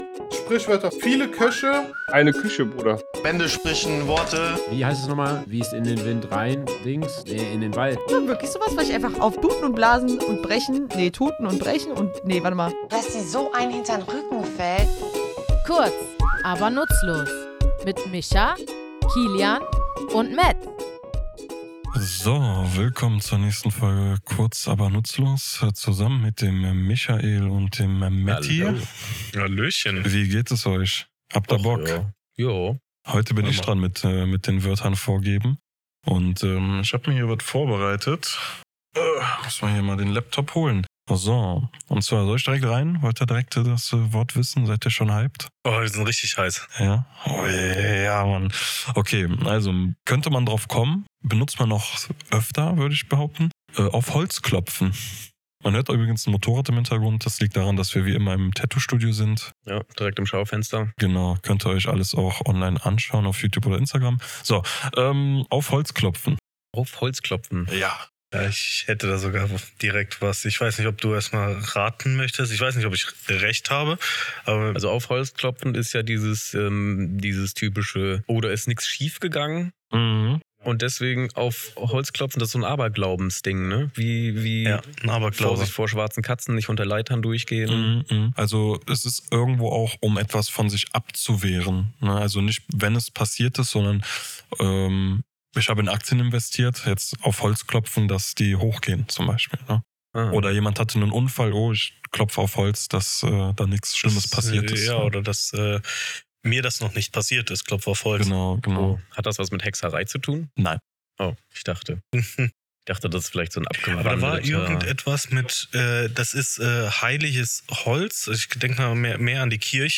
Comedy
entspannten Atmosphäre unseres Tattoostudios, während wir die